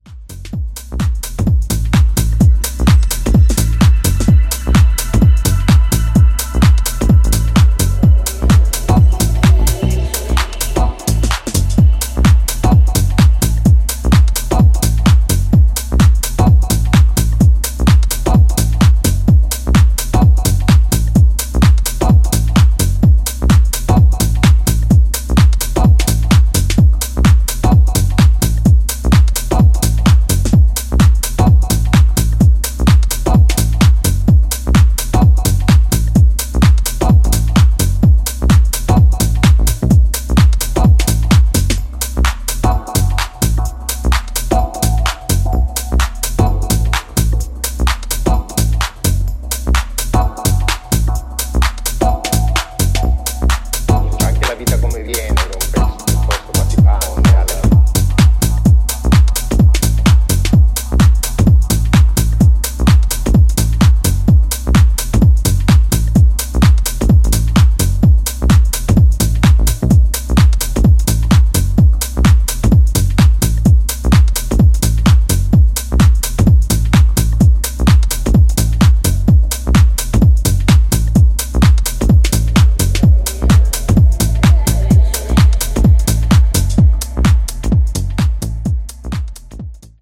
ジャンル(スタイル) TECH HOUSE